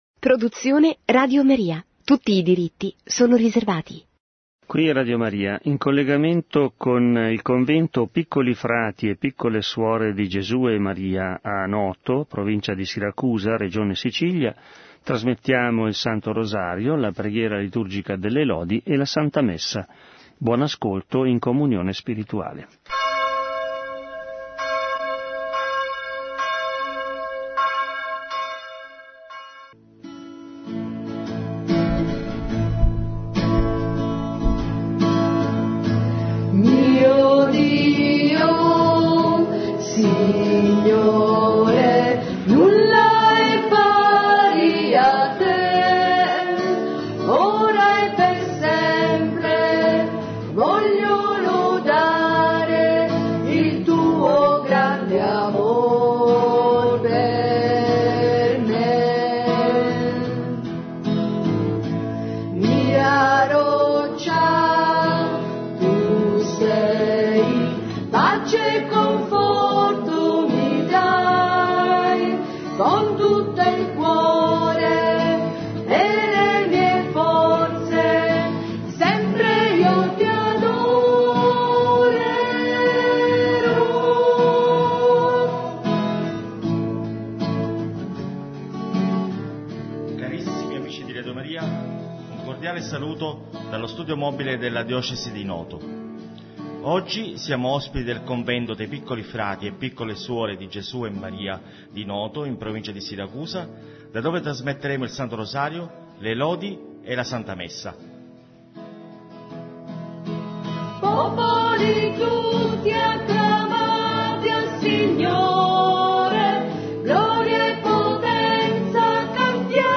ENTREVISTAS RADIOFÓNICAS aos pequenos v.v. ...